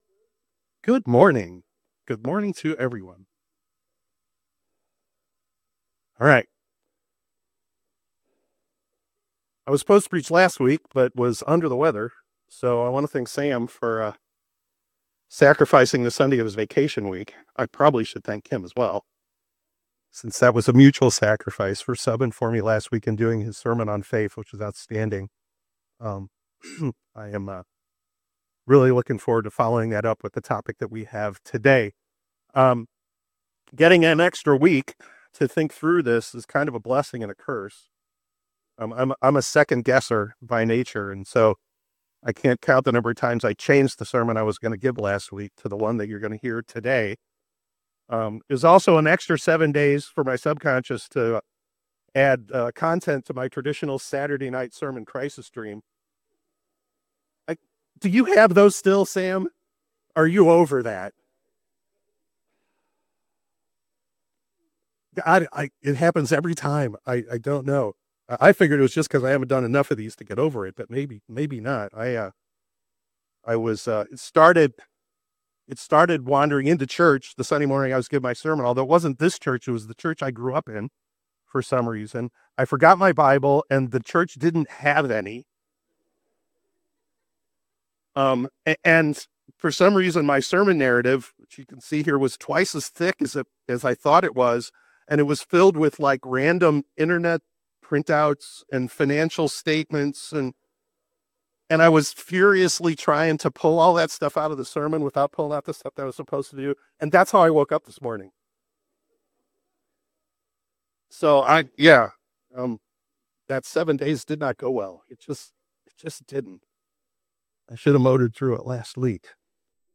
Discover the power of hope in this sermon out of Luke Chapter 1.